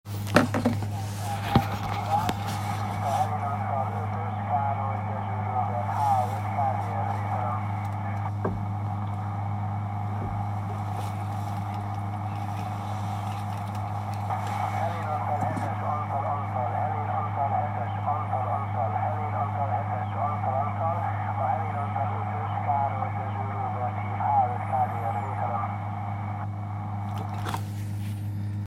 AM-ben Gyúró-majorban az UKW e.E vevőn